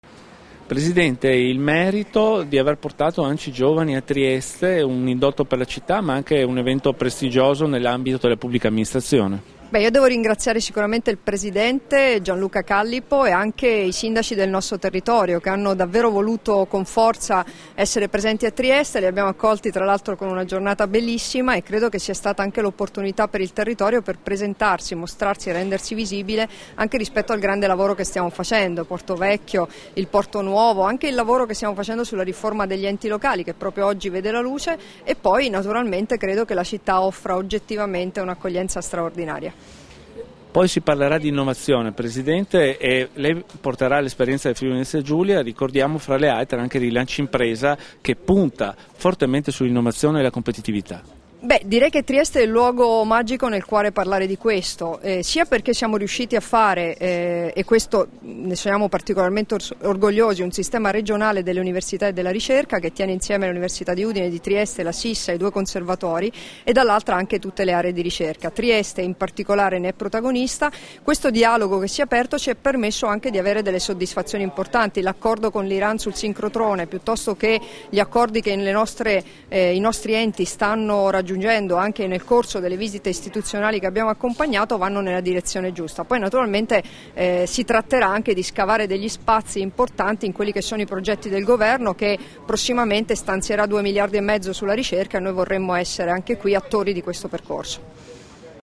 Dichiarazioni di Debora Serracchiani (Formato MP3) [1579KB]
alla settima assemblea nazionale ANCI Giovani, sul tema "La Città che vorrei", rilasciate alla Stazione Marittima di Trieste il 15 aprile 2016